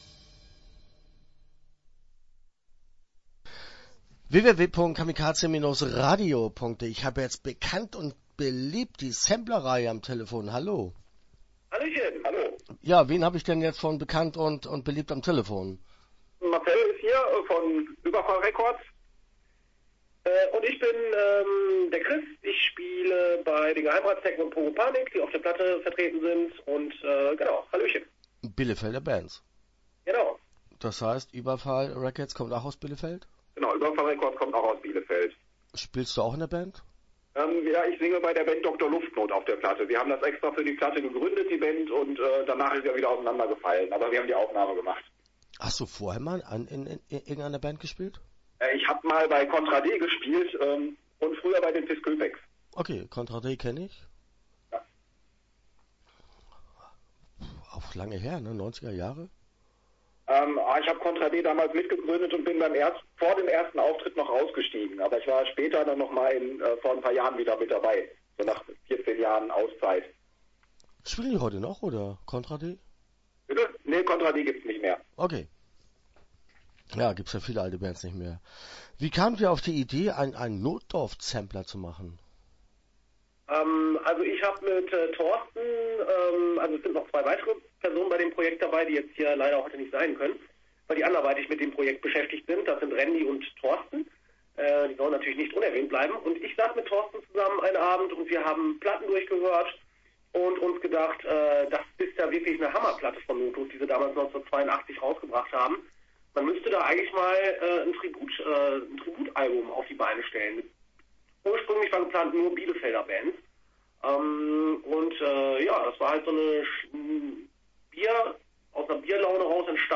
Start » Interviews » Bekannt & Beliebt